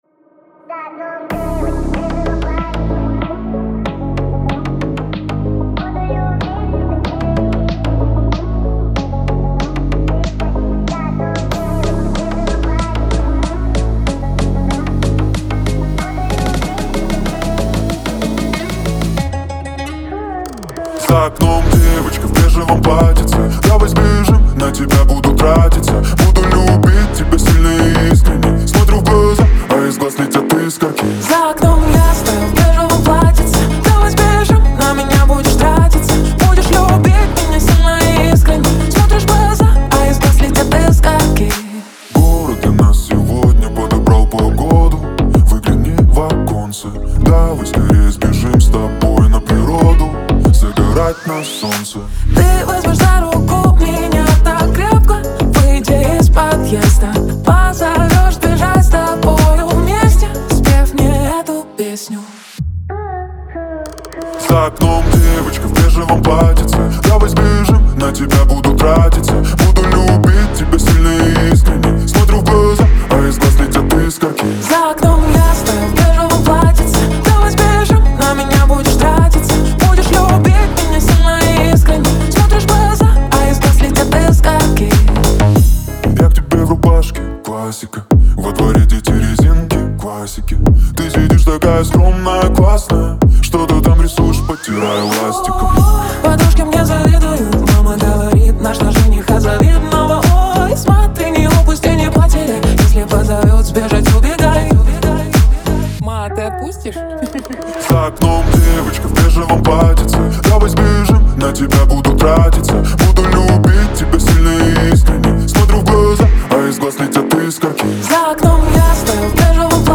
Классный трек в жанре поп музыка.